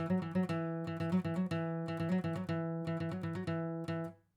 Acoustic guitar
Microphones Used: AKG 411
AKG 451
Master Tempo Track : 120 BPM
acoustic_ptrn_18.wav